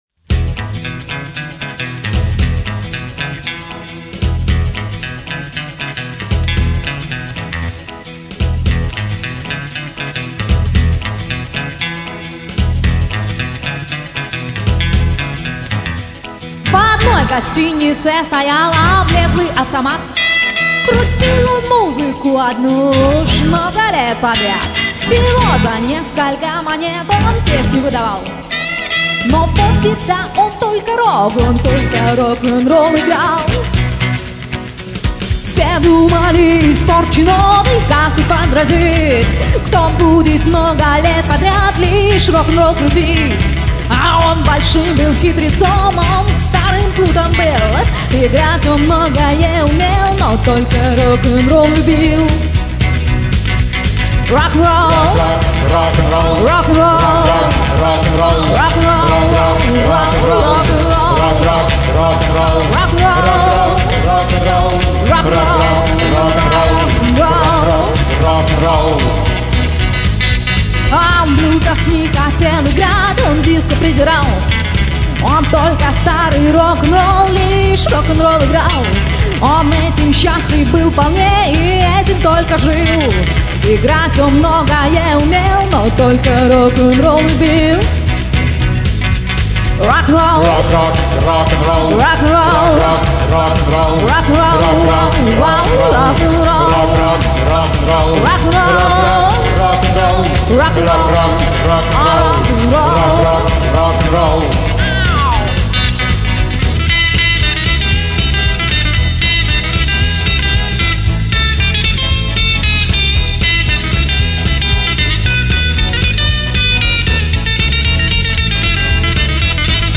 ...всё это Rock"n"Roll......и не только...
Демоальбом - г. Дмитров